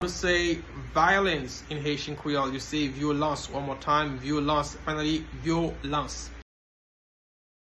Pronunciation:
Violence-in-Haitian-Creole-Vyolans-pronunciation-by-a-Haitian-teacher.mp3